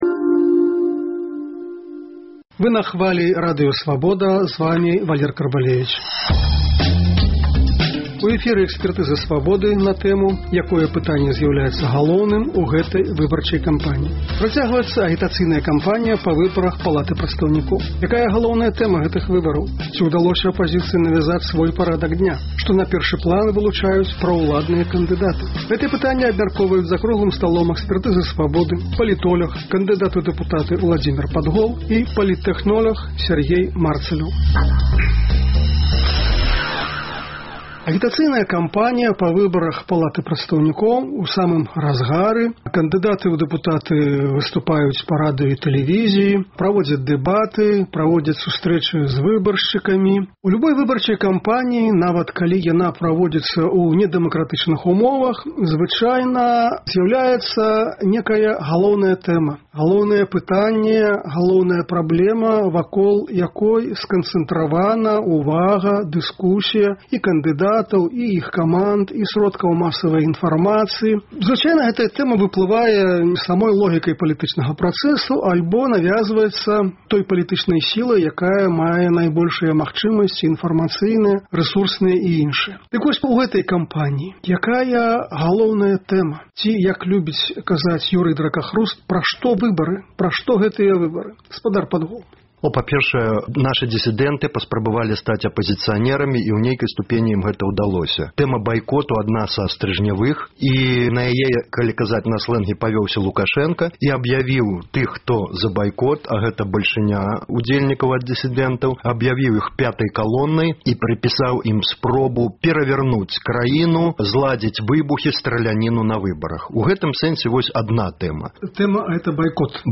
Гэтыя пытаньні абмяркоўваюць палітоляг